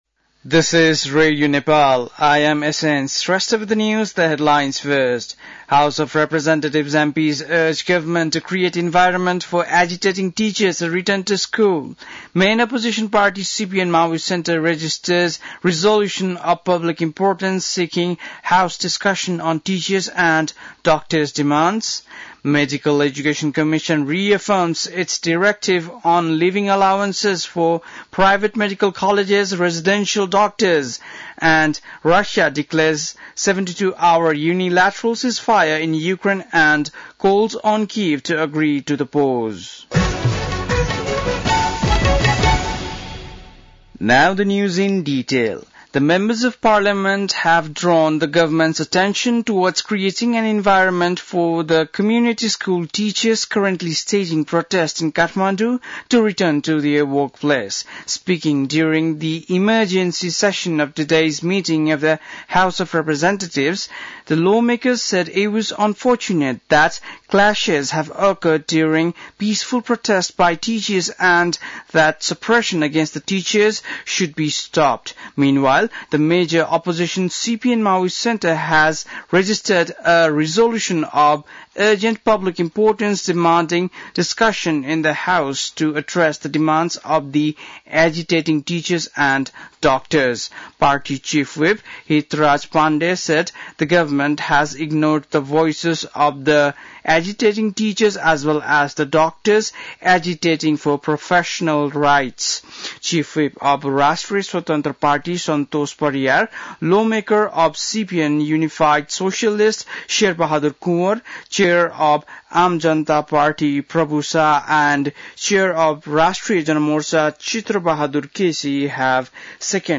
बेलुकी ८ बजेको अङ्ग्रेजी समाचार : १५ वैशाख , २०८२
8-pm-english-news-4.mp3